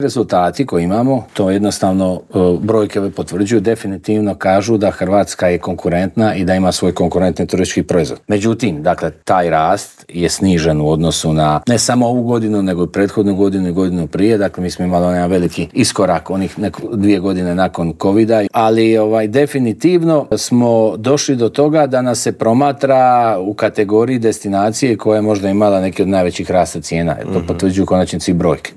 ZAGREB - Špica sezone je iza nas, a Hrvatska ponovno bilježi odlične turističke rezultate. Oduševila je predsezona, srpanj i kolovoz su bili na razini 2024. godine, a o svemu smo u Intervjuu tjedna Media servisa razgovarali s ministrom turizma i sporta Tončijem Glavinom.